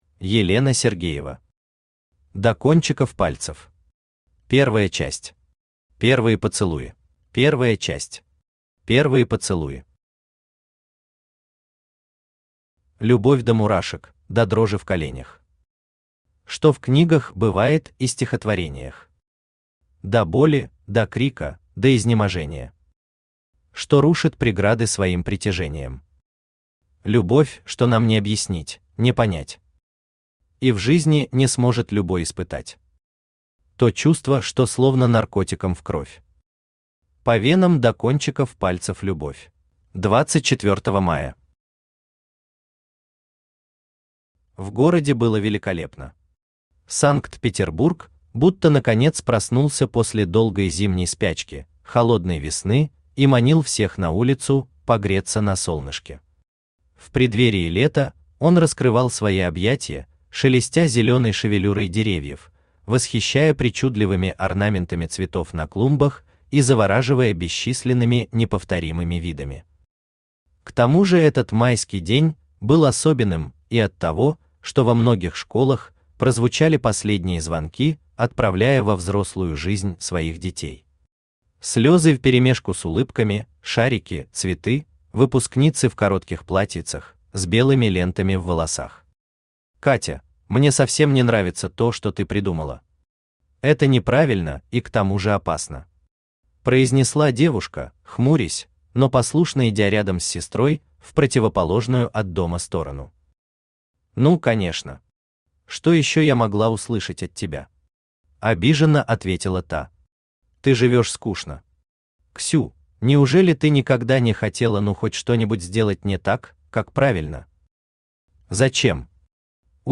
Аудиокнига До кончиков пальцев… Первая часть. Первые поцелуи…
Автор Елена Сергеева Читает аудиокнигу Авточтец ЛитРес.